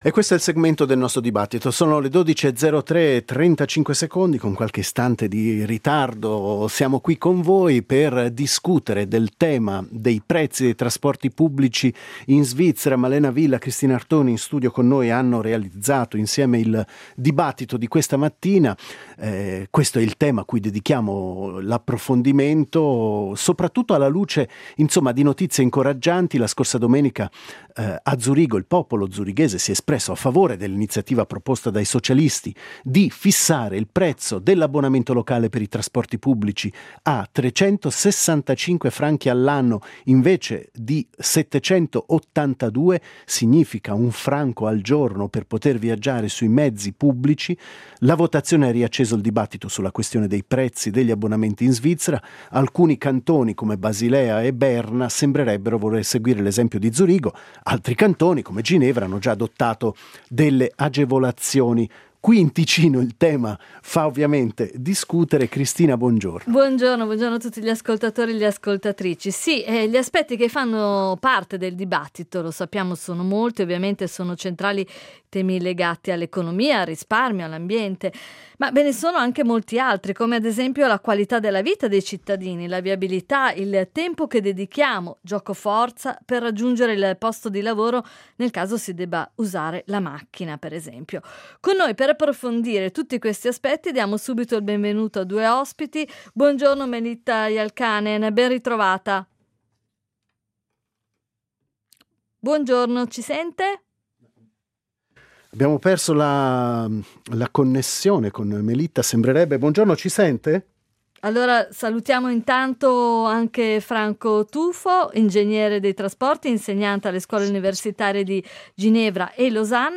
In onda il 3 ottobre 2025 su RSI